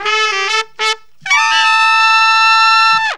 HORN RIFF 5.wav